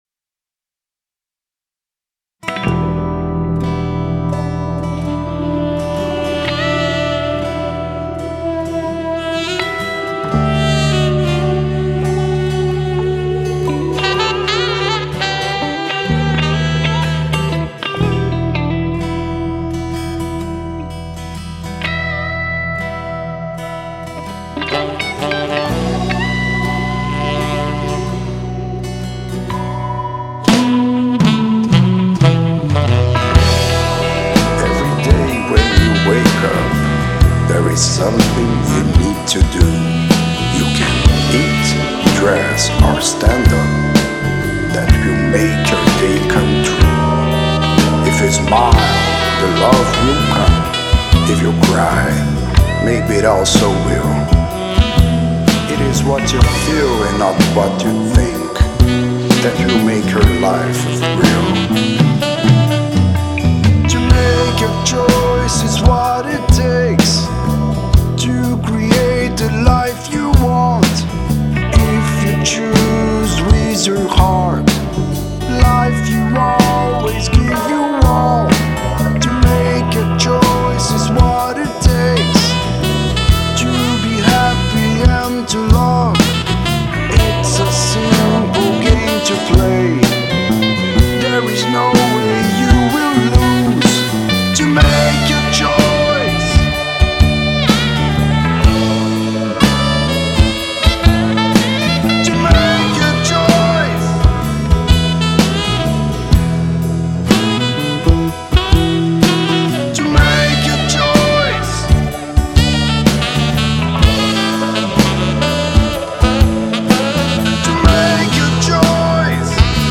Saxofone Tenor e Baritono